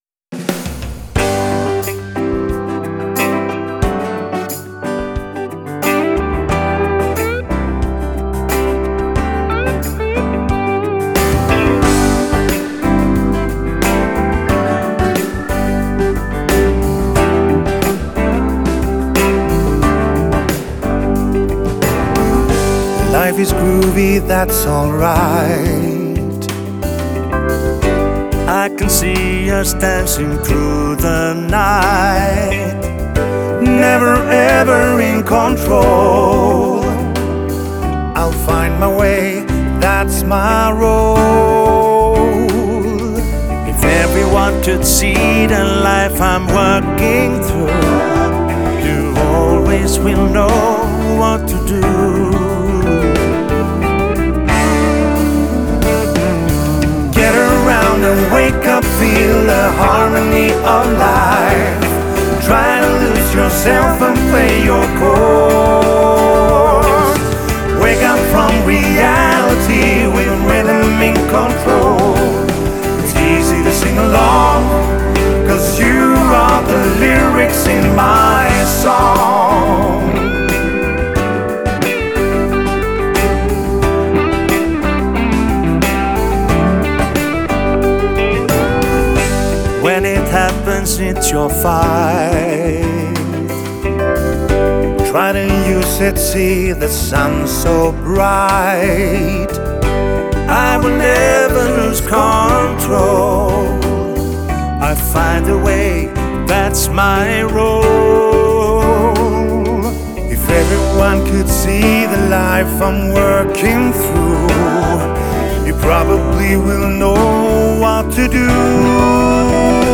PLAYBACK Bokning-och Kontaktinformation: Festband iHallabro Playbackcoverband